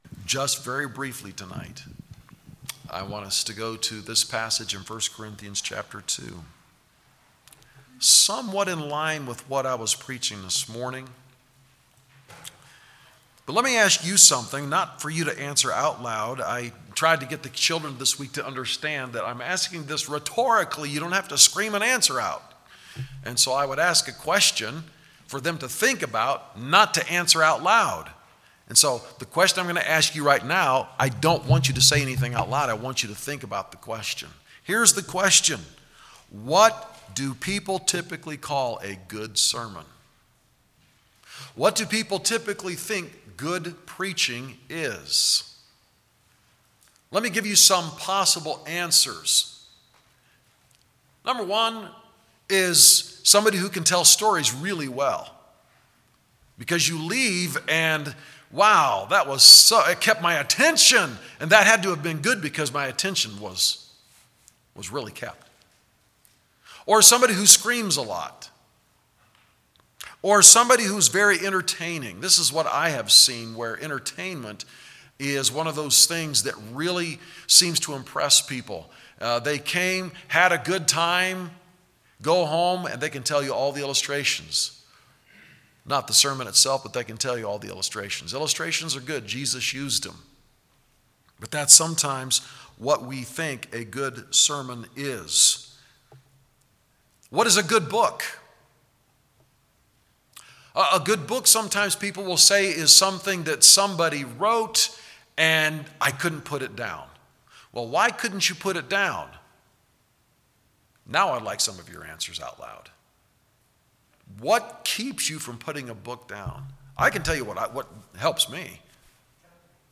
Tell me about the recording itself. Matthew 27:17 AM Service What moves man to choose the sinner over the sinless Son of God